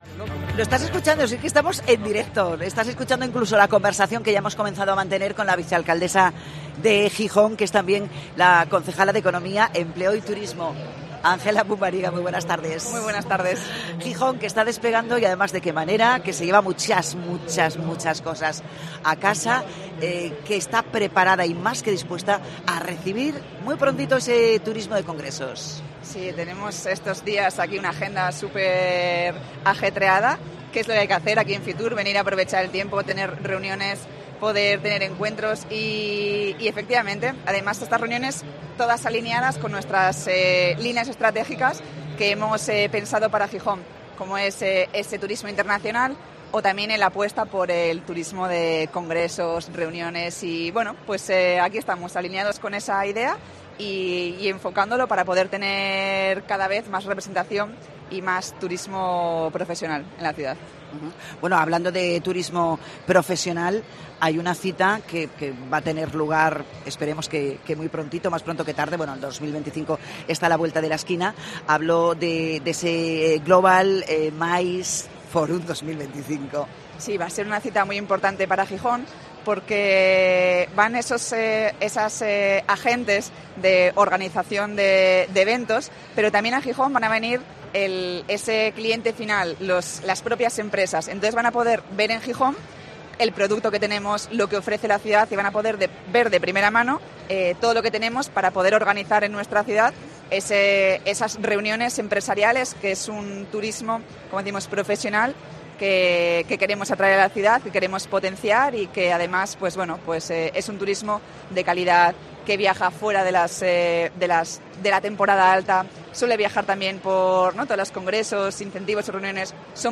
En el especial de COPE Asturias con motivo de FITUR 2024, hablamos con la vicealcaldesa del concejo y concejala de Turismo, Ángela Pumariega
FITUR 2024: Entrevista a Ángela Pumariega, vicealcaldesa y concejala de Turismo de Gijón